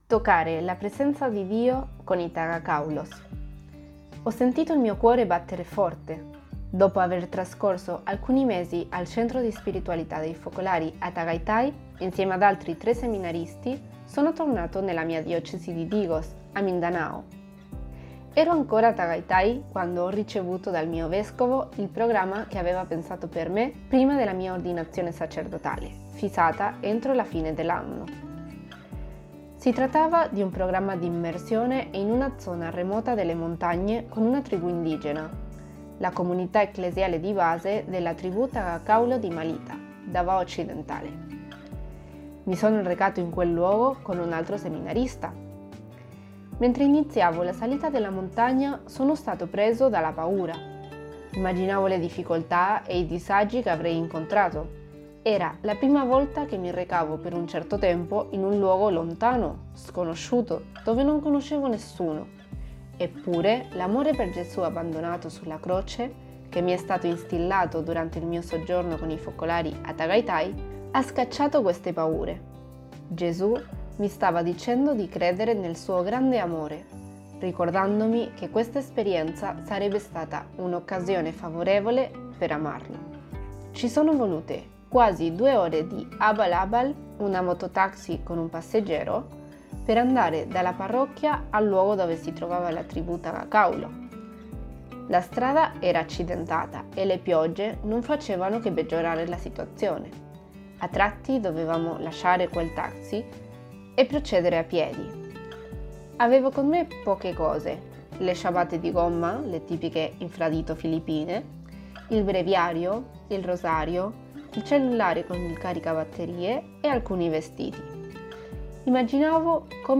Persona e famiglia > Audioletture